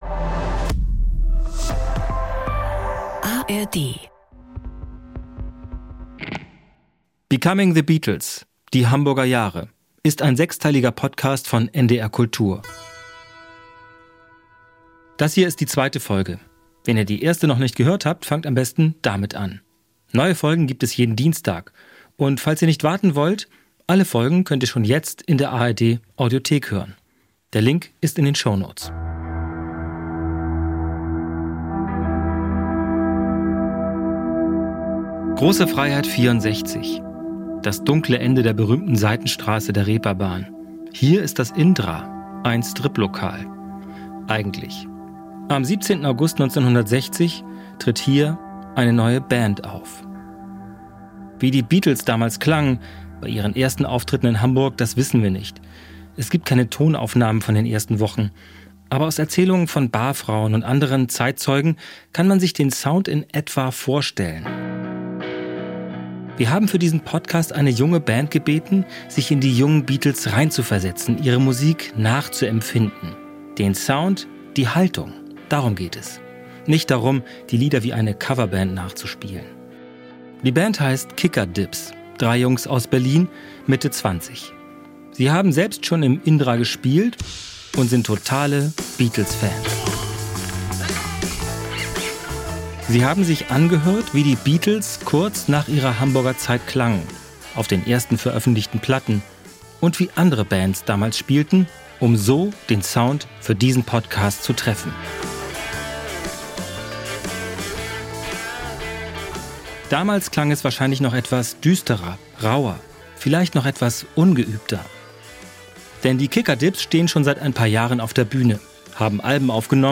Ein Podcast mit viel Musik, O-Tönen von Zeitzeugen und seltenem Archivmaterial.